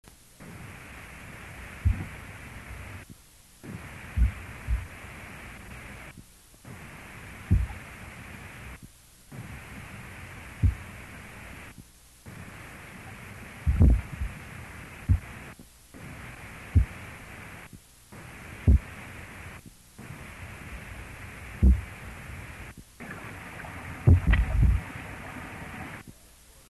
Type of sound produced grunts, booms, thumps Sound production organ swim bladder Sound mechanism vibration by contraction of associated muscles
Behavioural context feeding (with other species) & very mild manual stimulation
recorded with lowpass filter 1200 Hz (very noisy)